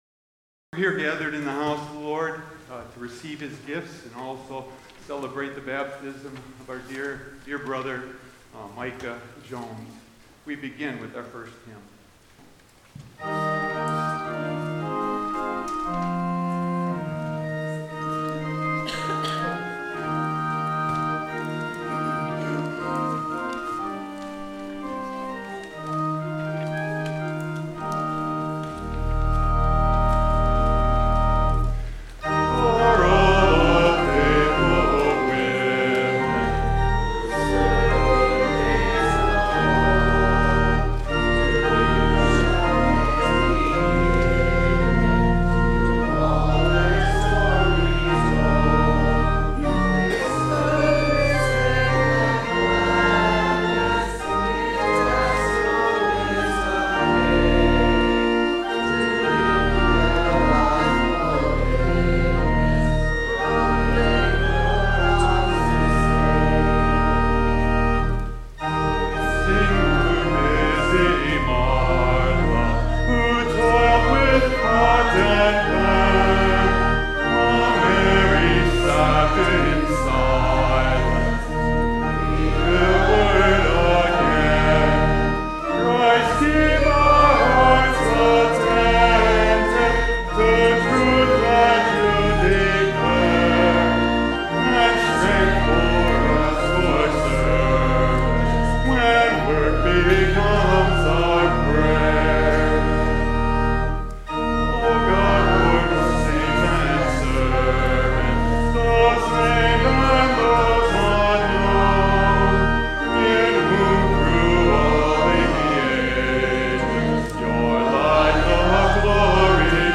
Service from July 20
Permission to podcast/stream the music in this service obtained from ONE LICENSE with license A-717990.